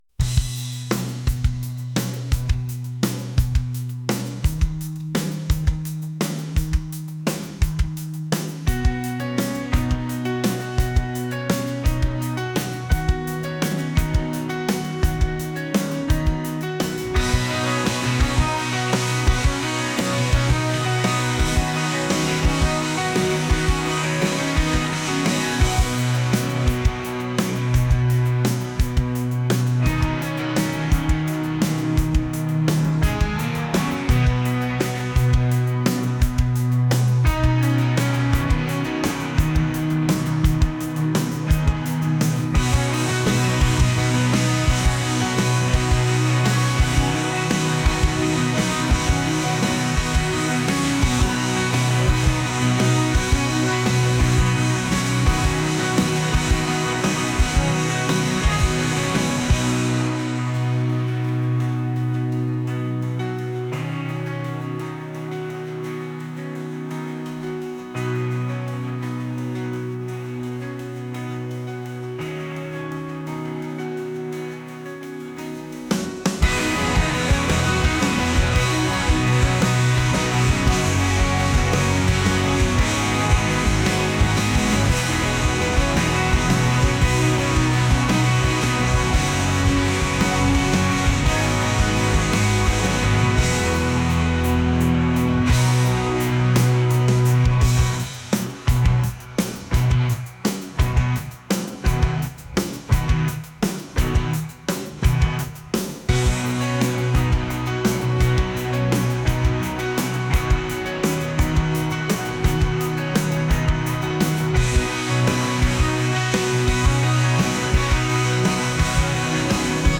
alternative | rock | indie